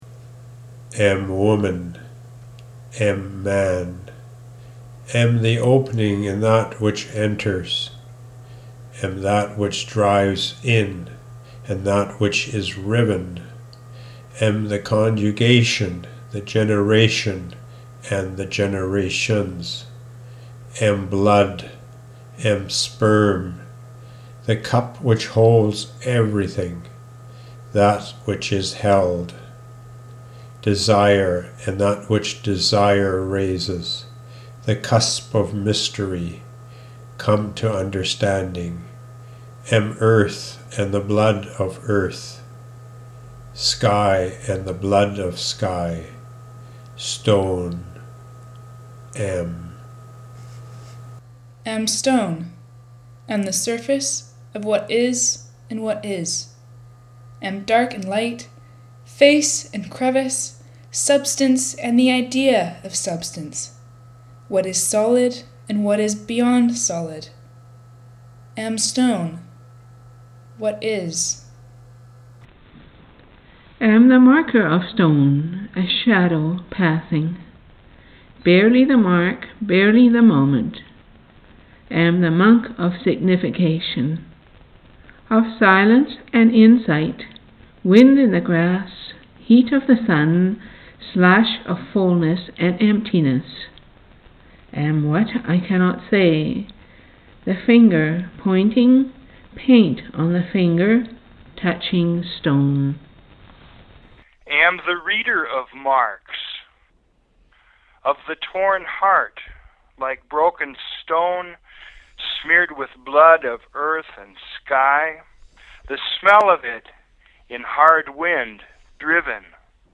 vocal performance by all four
four-voices-poem.mp3